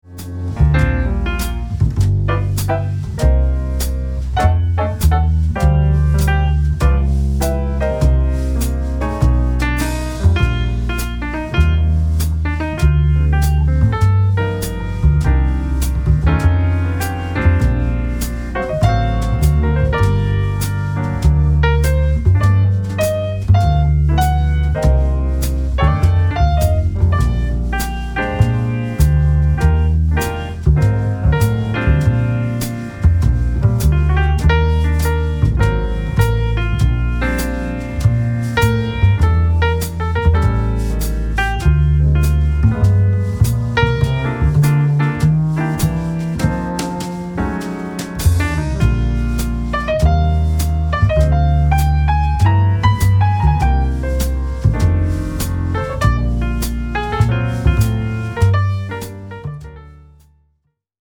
Played at tasteful volume that compliments conversations.